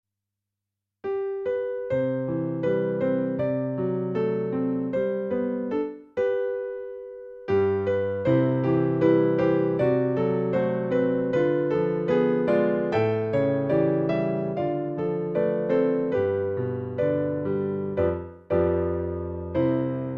ピアノで弾いてみました。
全部で24秒位なので、「曲」と言うものでもなく、「表現」？しかも手で弾いたまんまで何もイジってないので、ちょっとヘタですがお許しを～～♪